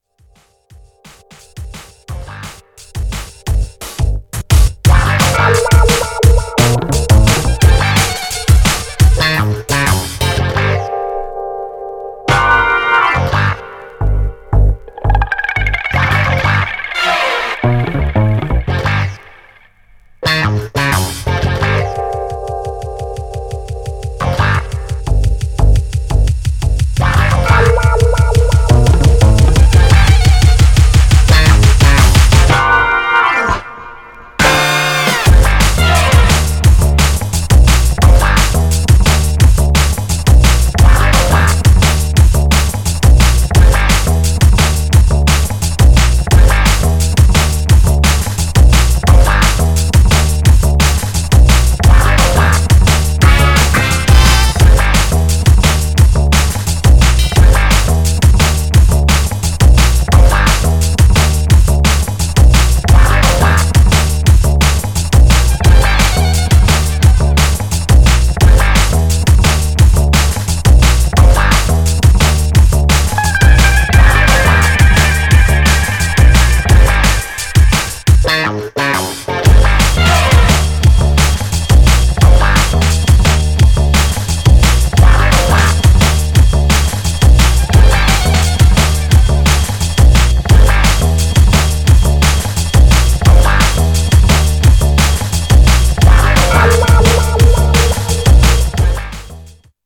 Styl: Techno Vyd�no